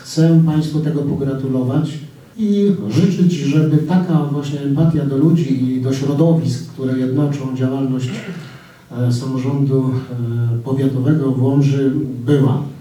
W Starostwie Powiatowym w Łomży odbyło się dziś spotkanie opłatkowe.
Wicemarszałek województwa podlaskiego, Marek Olbryś mówił, że zawsze jak jest na spotkaniu opłatkowym w starostwie łomżyńskim, to panuje tu zawsze atmosfera koncyliacyjna, która w innych miejscach jest rzadko spotykana